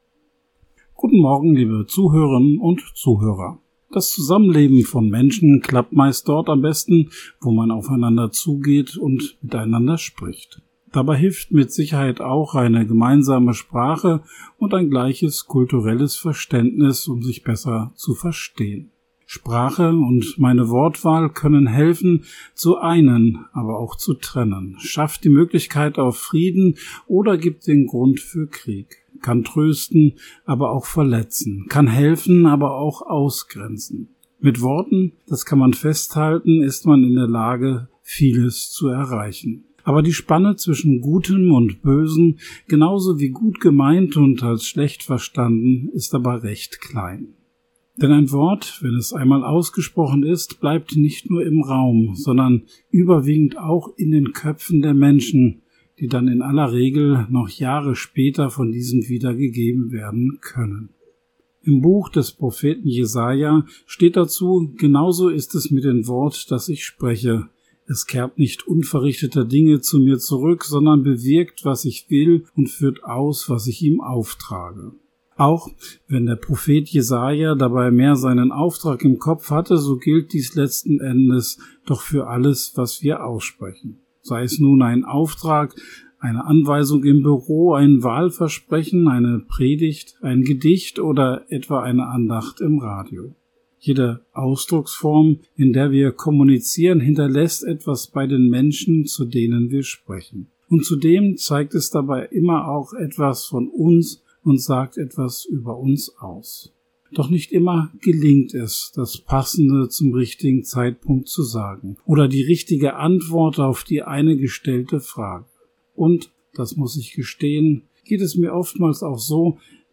Radioandacht vom 28. Februar